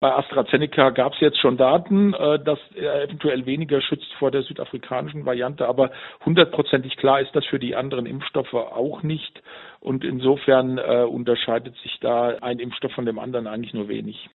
Virologe